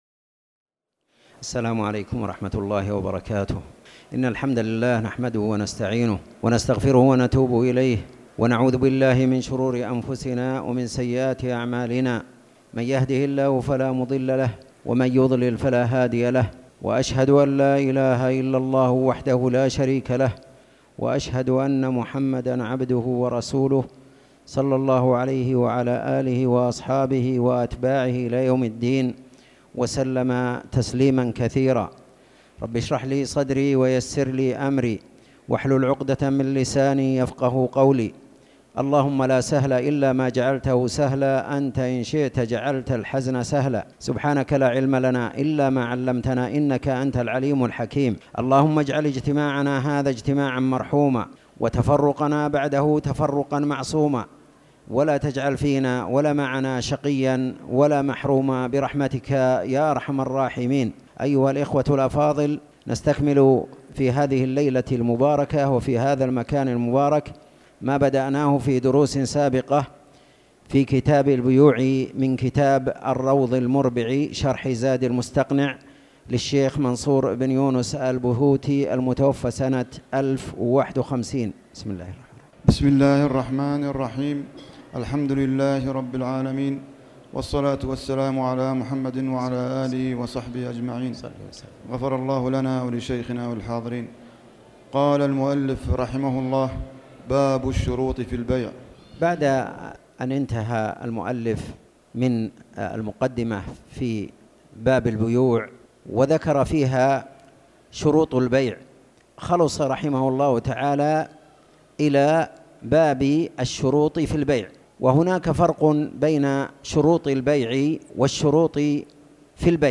تاريخ النشر ٢٥ جمادى الآخرة ١٤٣٩ هـ المكان: المسجد الحرام الشيخ